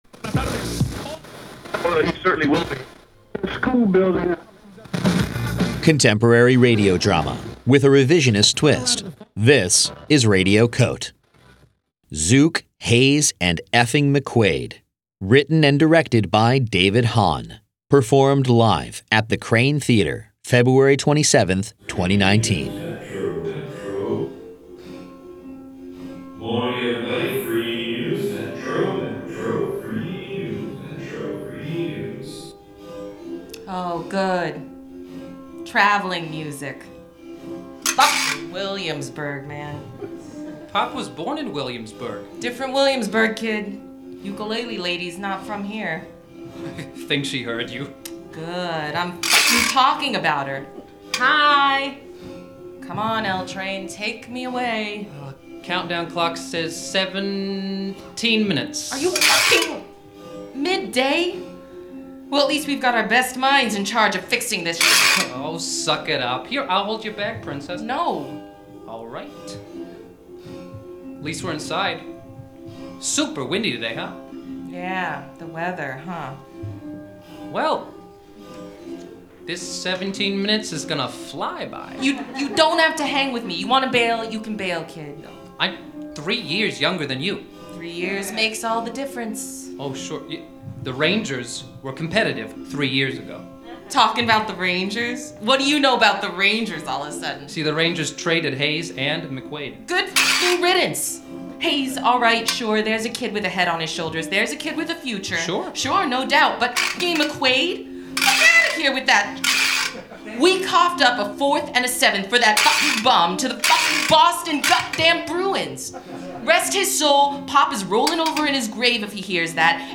performed live at Radio COTE: 24-hour Newsroom in the 2019 FRIGID Festival at The Kraine Theater, New York City, February 27, 2019